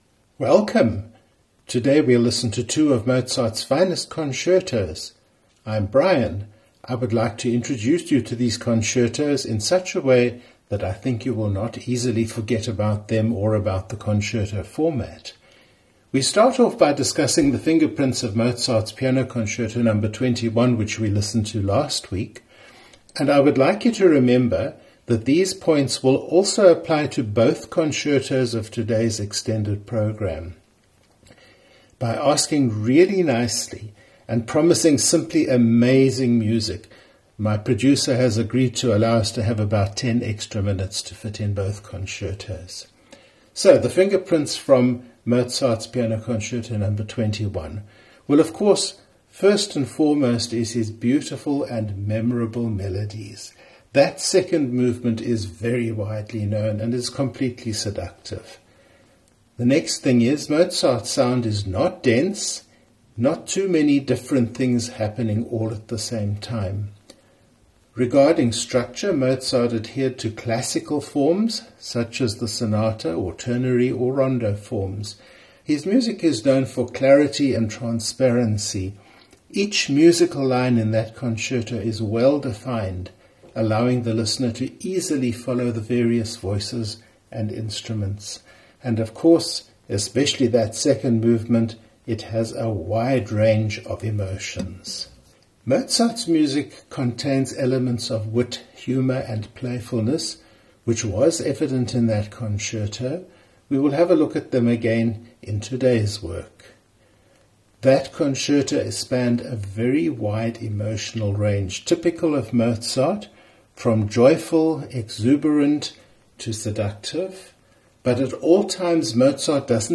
Two A major Concerto’s by Mozart today.
Mozart’s Concertos for Piano and for Clarinet, are both in A major.
A major, with its bright and open character, was often seen as a key suitable for expressing joy, celebration, and positive emotions.